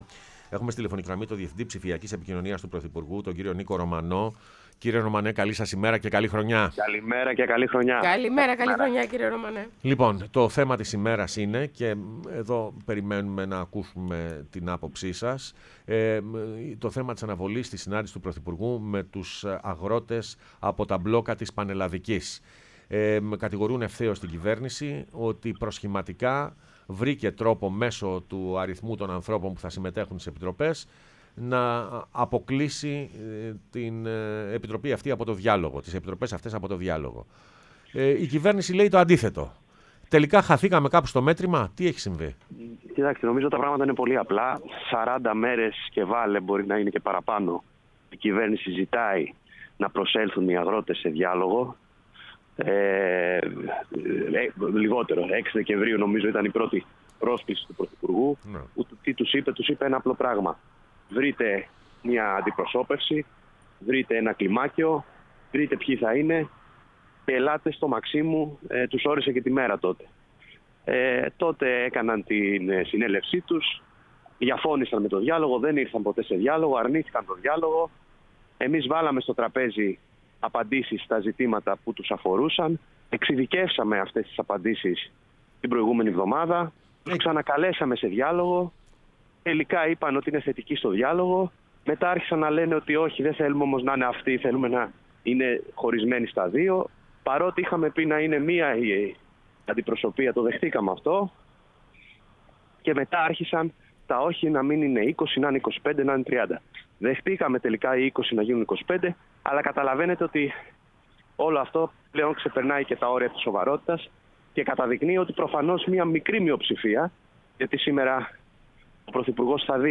Νίκος Ρωμανός, Διευθυντής Ψηφιακής Επικοινωνίας του Πρωθυπουργού, μίλησε στην εκπομπή «Πρωινές Διαδρομές»